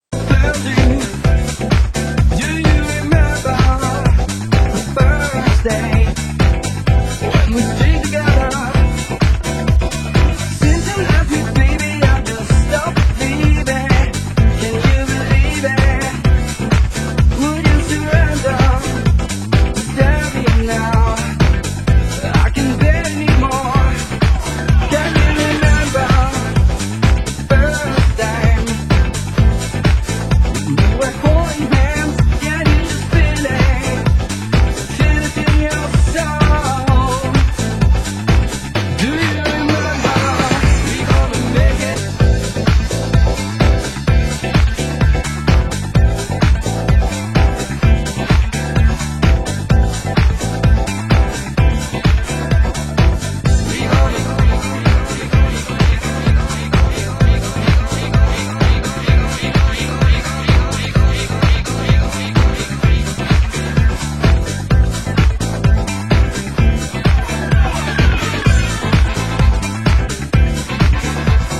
Genre: French House